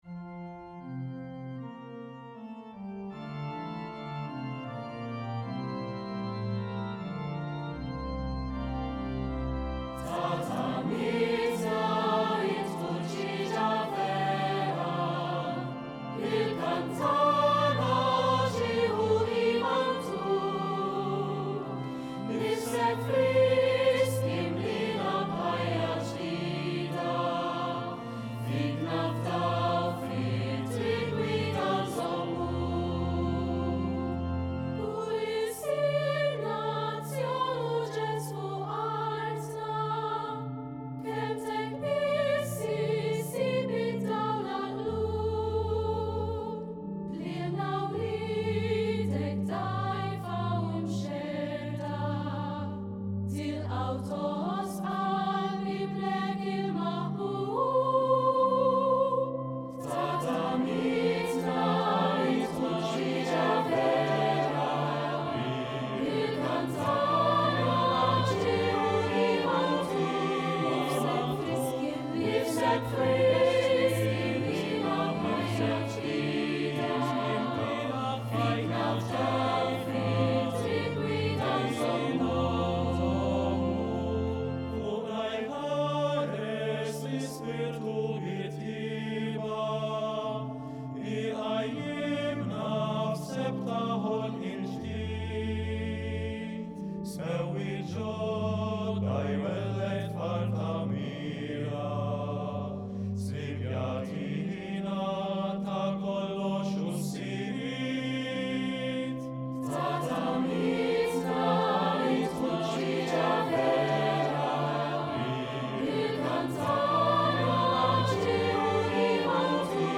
Kor